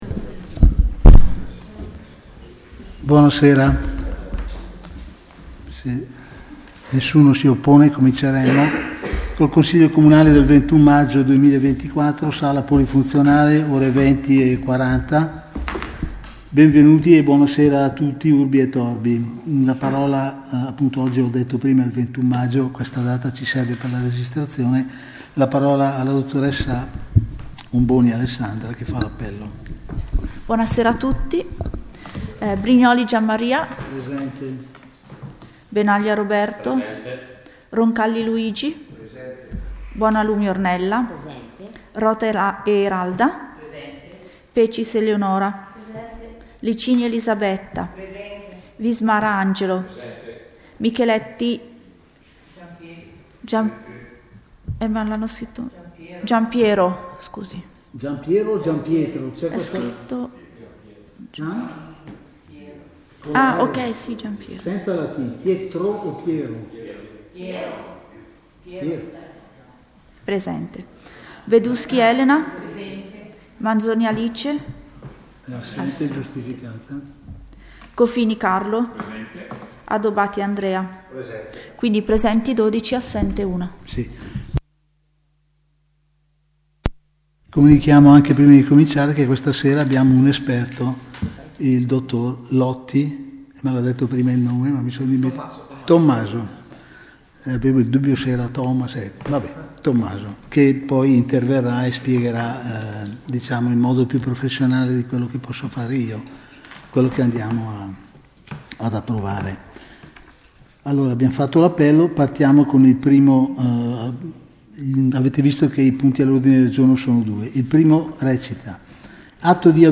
Registrazione del consiglio comunale del 21 Maggio 2024 - Comune di Paladina